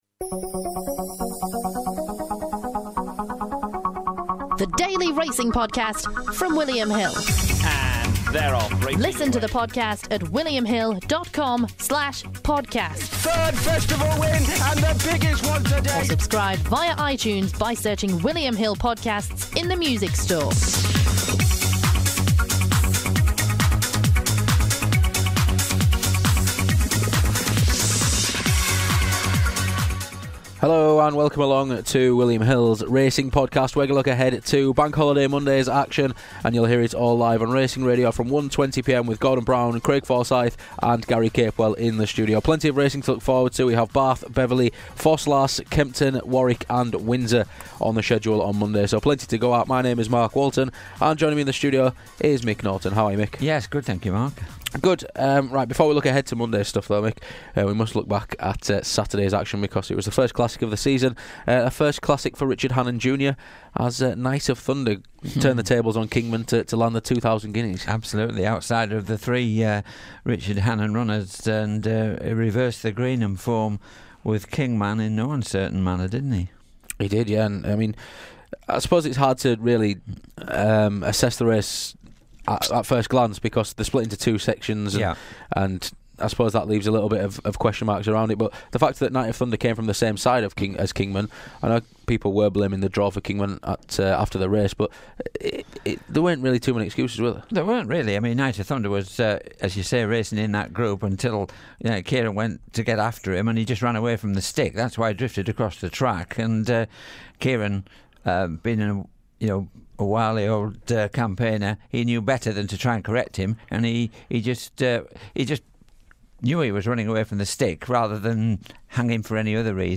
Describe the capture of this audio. in the studio on this edition of the podcast.